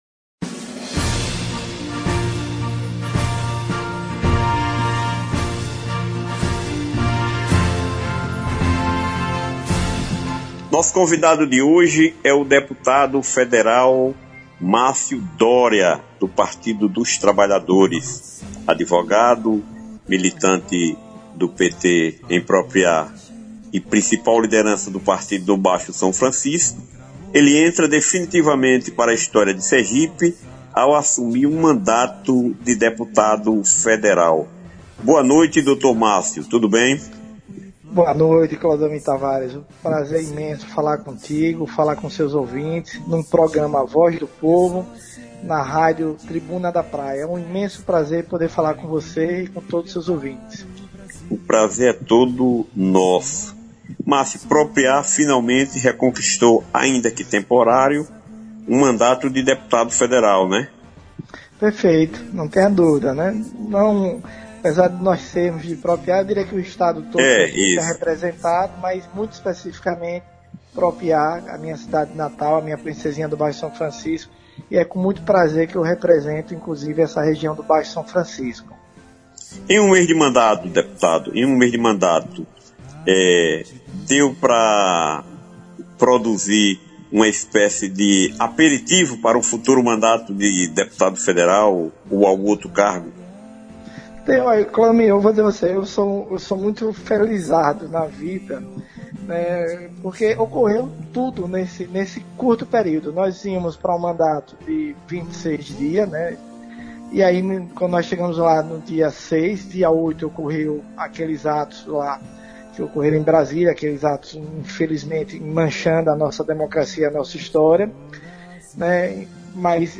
O deputado federal Márcio Dória (PT/SE) foi o entrevistado desta quinta-feira, 27, do programa A HOZ DO POVO